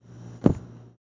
爆头音效
标签： 游戏 headshot 音效
声道立体声